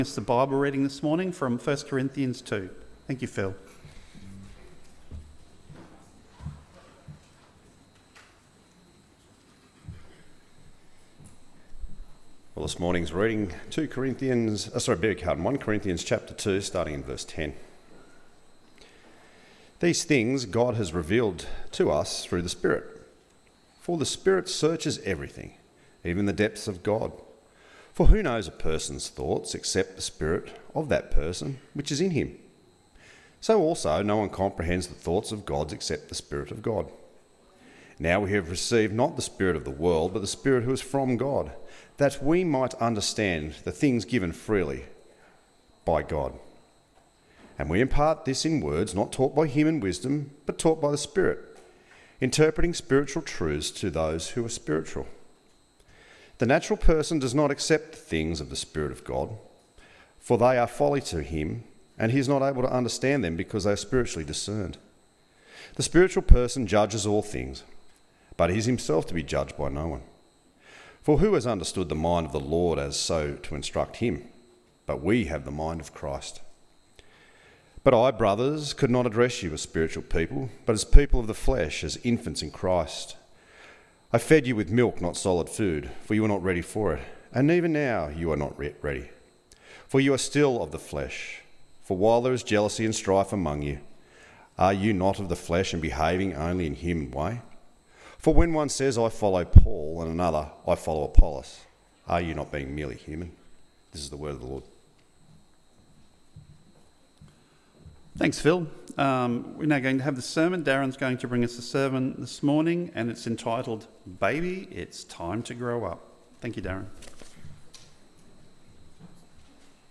Baby It’s Time To Grow Up AM Service